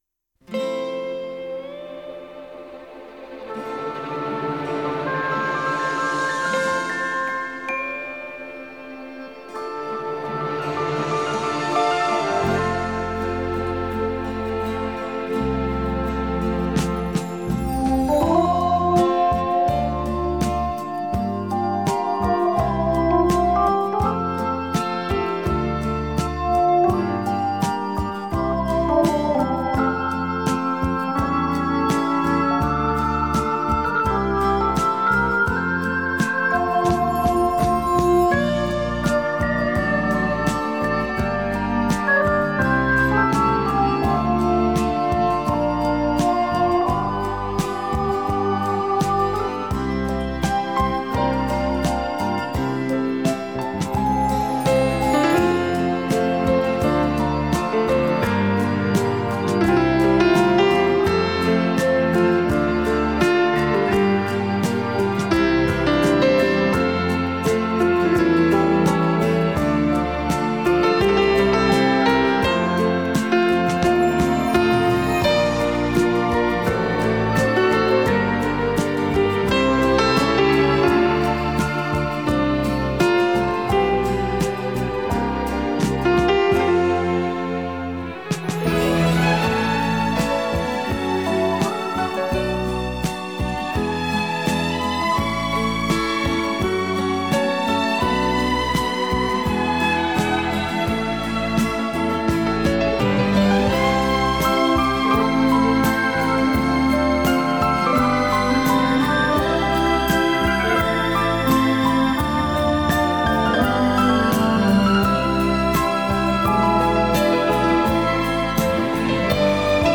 Жанр: Instrumental